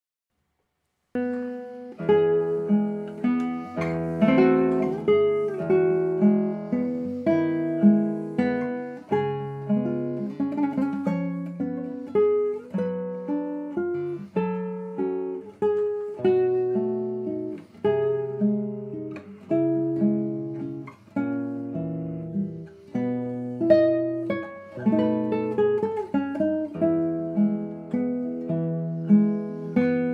Does Not Contain Lyrics
E Major
Andante